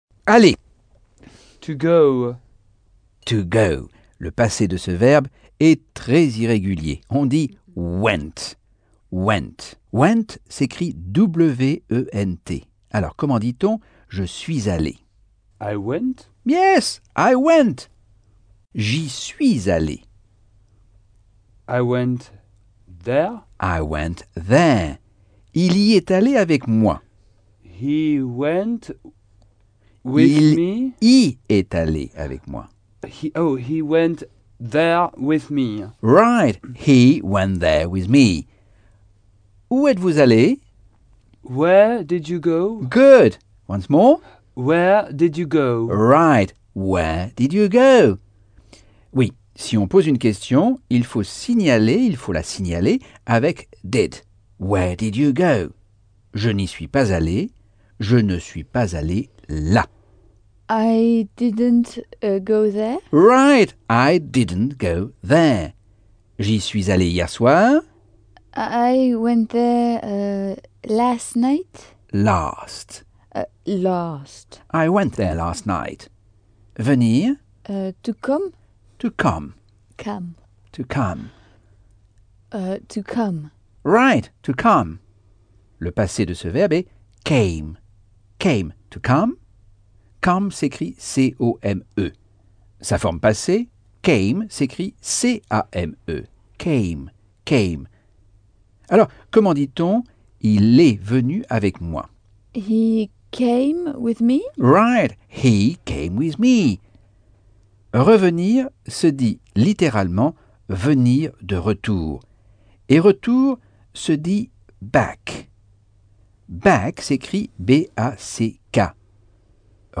Leçon 4 - Cours audio Anglais par Michel Thomas - Chapitre 7